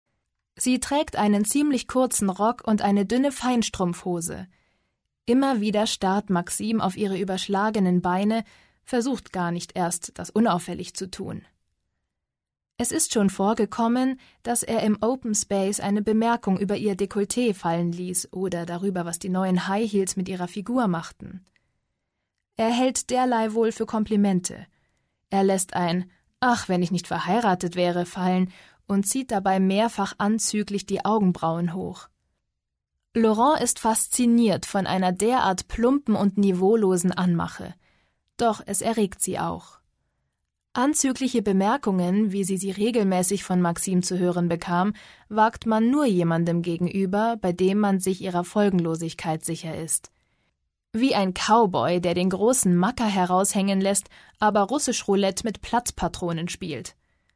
Als freiberufliche Sprecherin bin ich vorrangig in den Bereichen Werbung, Synchron, E-Learning, Voice Over und natürlich Hörbuch aktiv.